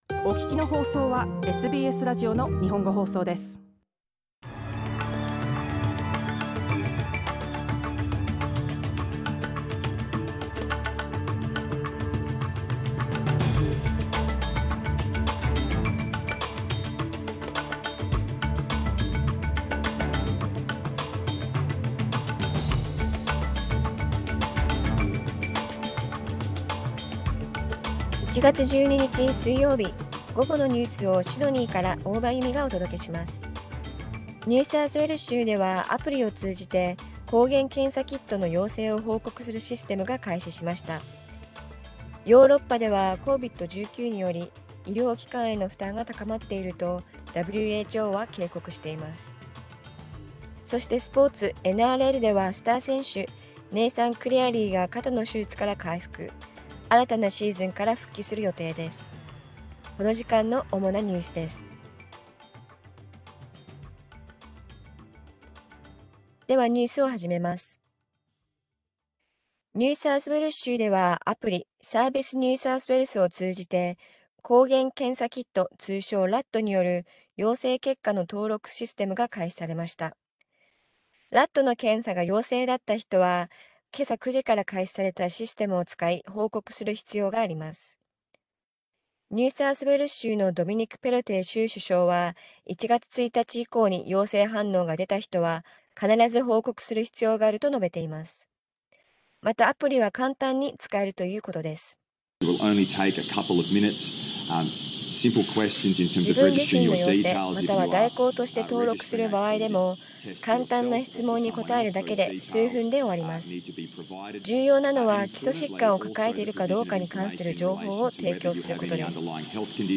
1月12日午後のニュース
Afternoon news in Japanese, 12 January 2022